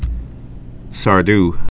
(sär-d), Victorien 1831–1908.